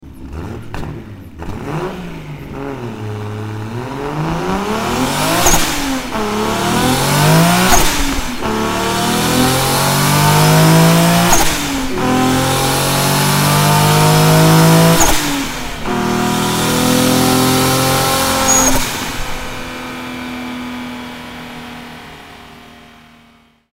Звук отсечки у мотоцикла
• Категория: Мотоциклы и мопеды
• Качество: Высокое